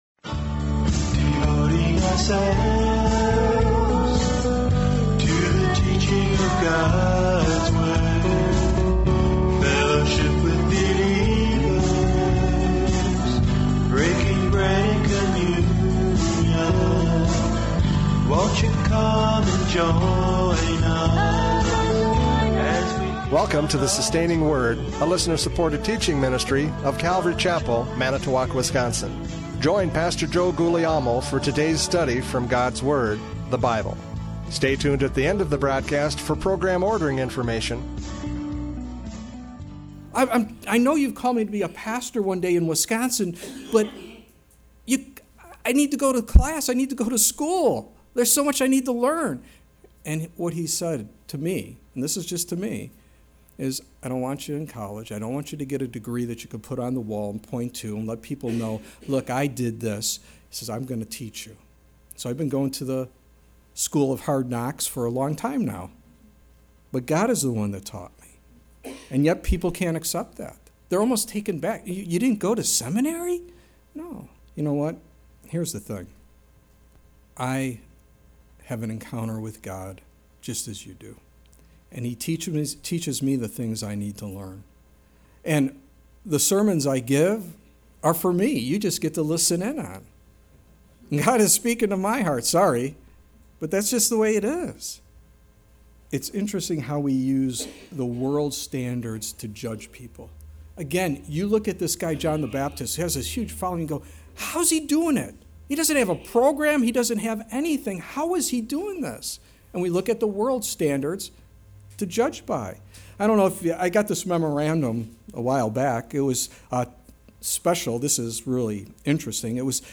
John 1:19-28 Service Type: Radio Programs « John 1:19-28 Witness to the Religious!